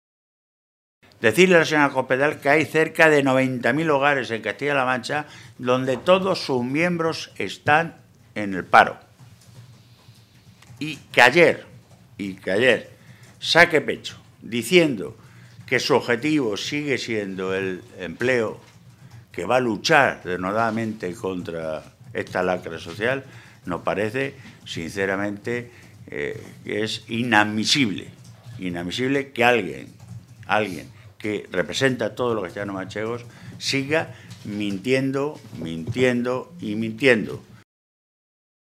Jesús Fernández Vaquero, Secretario de Organización del PSOE de Castilla-La Mancha
Cortes de audio de la rueda de prensa